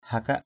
hakak.mp3